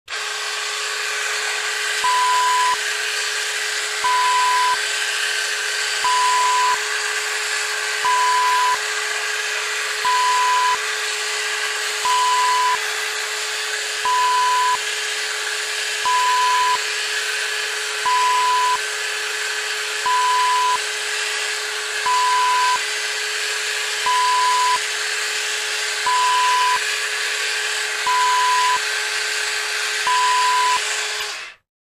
Electric shaver sound effect .wav
Description: Electric shaver turns on, shaves, and turns off
Properties: 48.000 kHz 16-bit Stereo
A beep sound is embedded in the audio preview file but it is not present in the high resolution downloadable wav file.
electric-shaver-preview-1.mp3